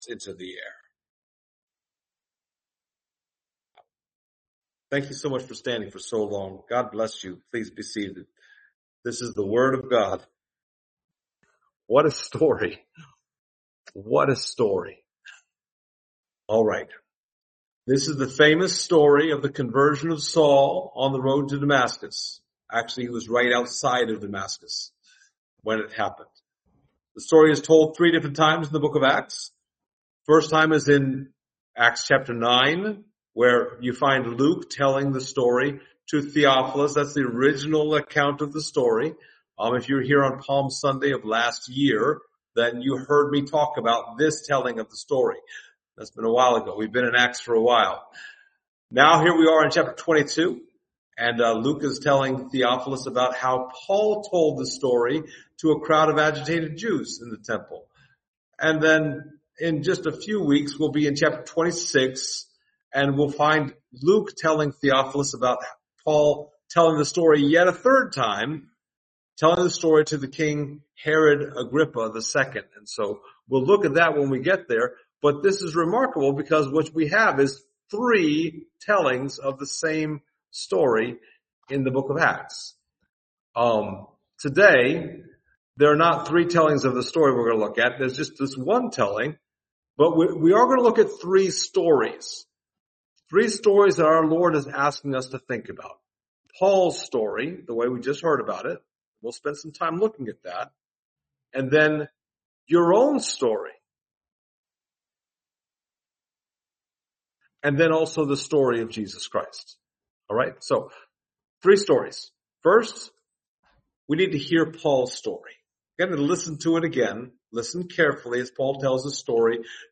THE STORY ONLY YOU CAN TELL | Dundalk's First Baptist Church